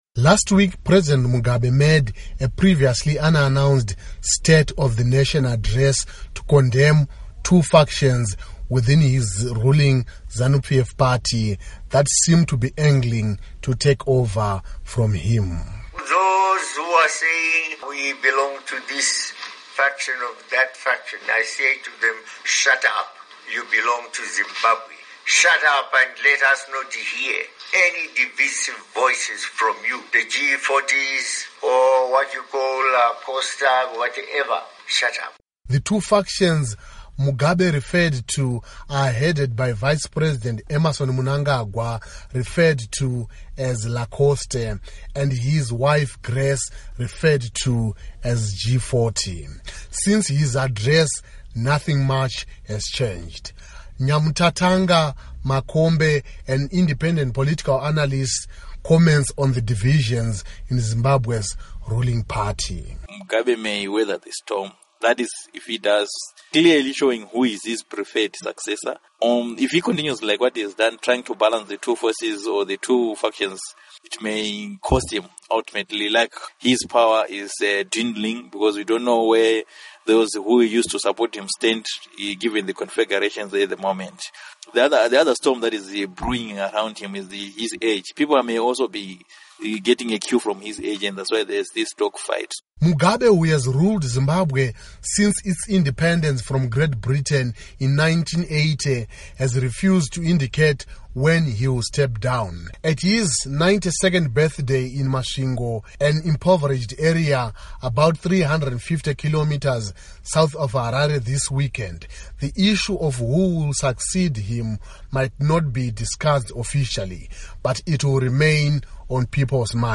Report on Mugabe Succession